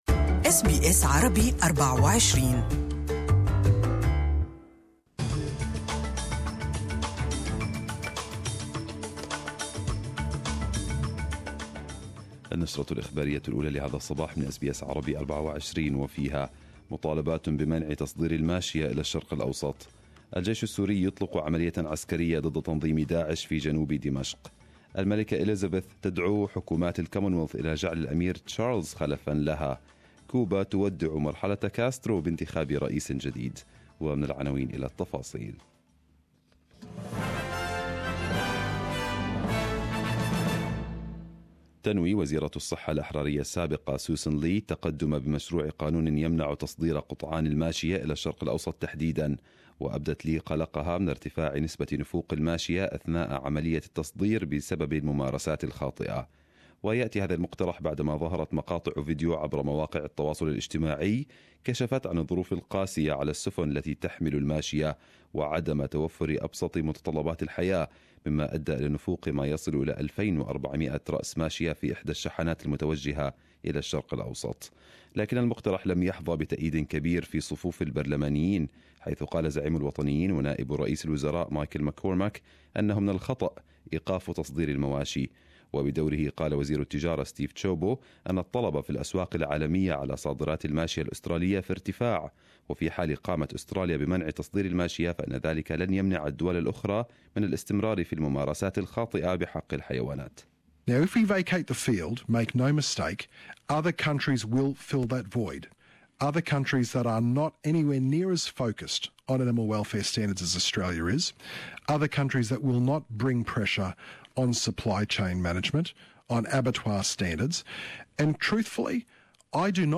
Arabic News Bulletin 20/04/2018